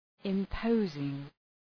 Προφορά
{ım’pəʋzıŋ}